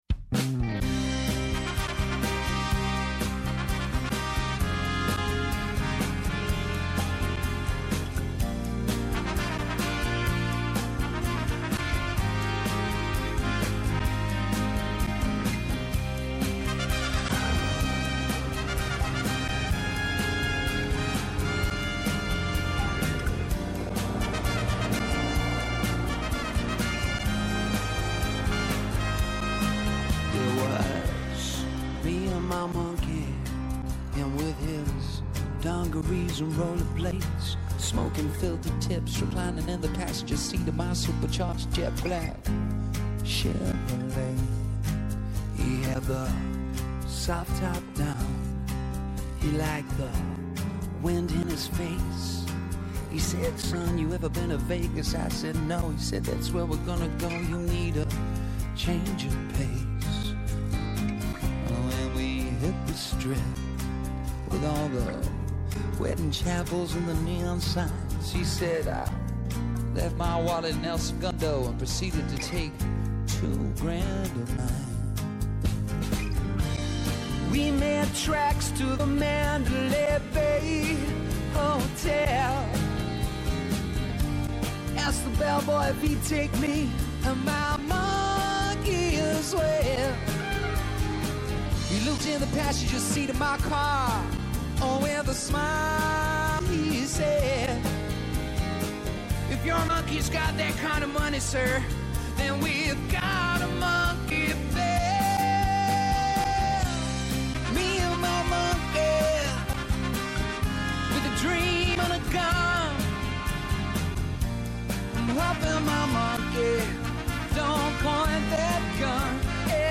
Από Δευτέρα έως Πέμπτη 11 με 12 το μεσημέρι στο Πρώτο Πρόγραμμα.
Έγκριτοι επιστήμονες, καθηγητές και αναλυτές μοιράζονται μαζί μας τις αναλύσεις τους και τις γνώσεις τους.